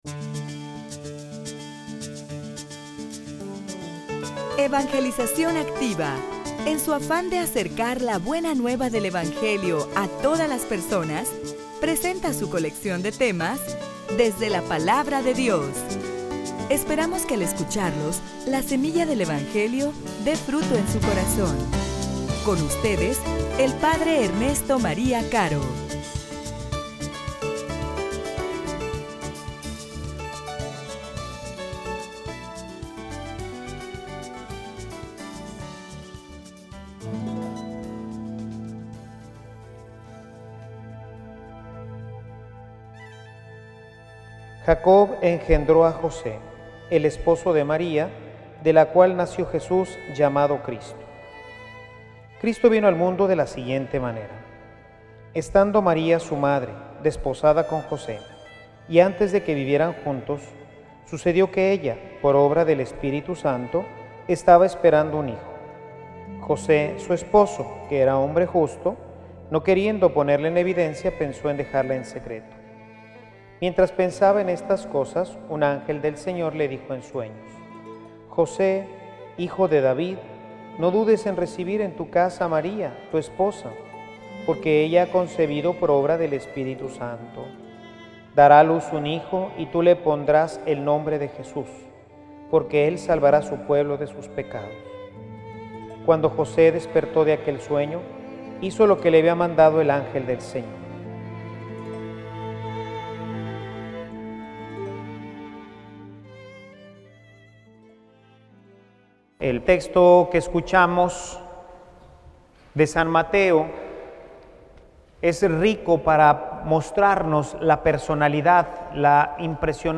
homilia_Un_varon_justo_para_un_hogar_santo.mp3